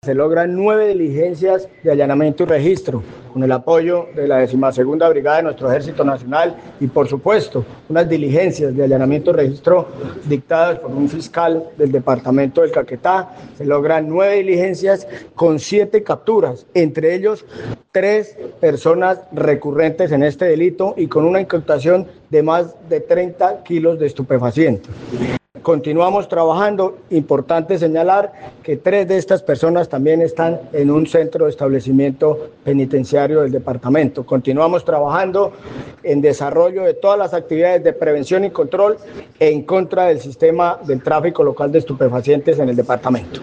Así lo dio a conocer el comandante de la Policía Caquetá, coronel Julio Hernando Guerrero Rojas, quien manifestó, además, que, tres de estos capturados, eran actores recurrentes en delitos como el tráfico ilegal de estupefacientes.
CORONEL_JULIO_GUERRERO_MICROTRAFICO_-_copia.mp3